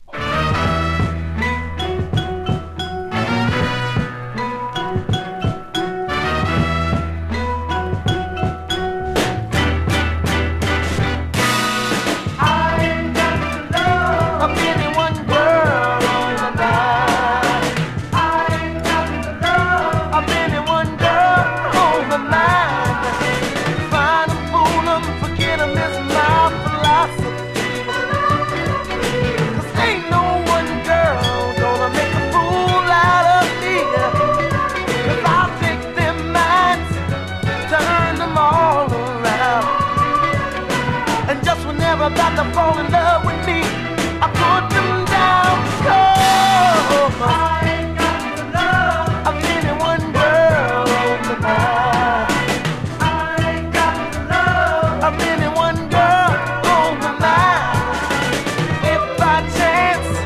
60’sソウル